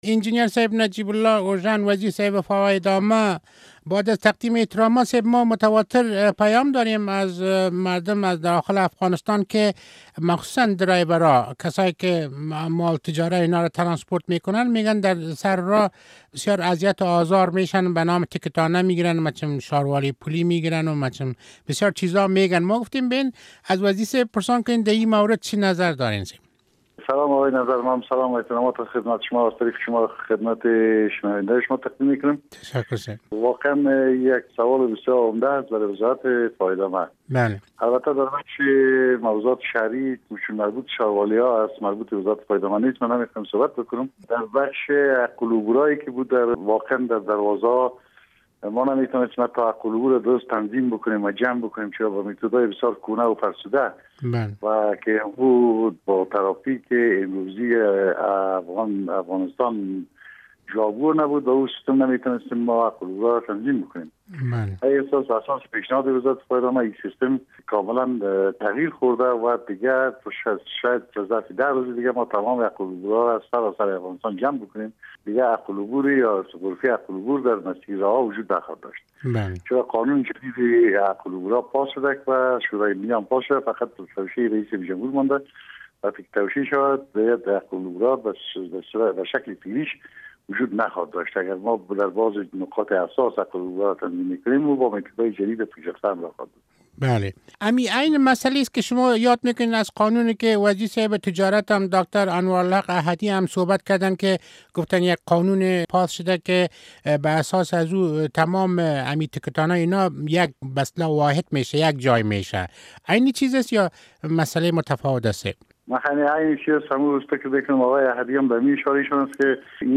مصاحبه با انجنیرنجیب الله اوژن وزیر فواید عامهء افغانستان درمورد مشکلات موجود در شاهراه ها